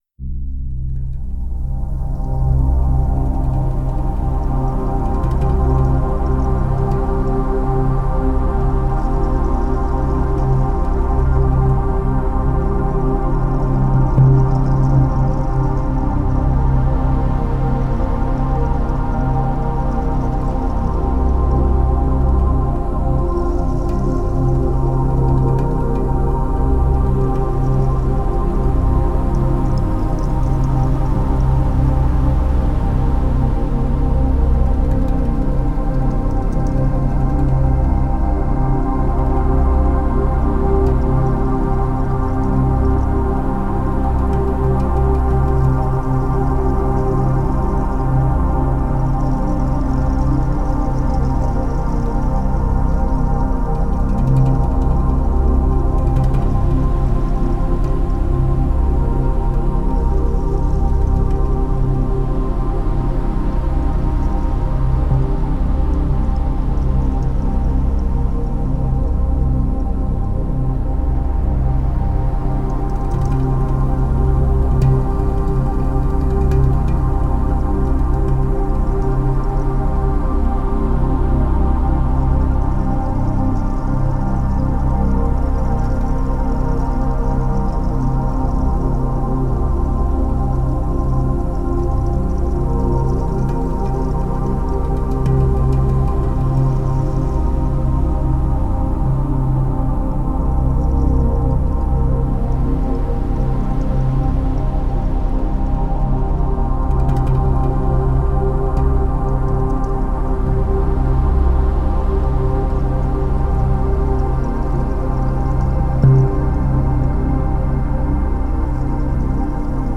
Die Frequenz-Melodie kann Dir helfen, in die Stille zu gehen.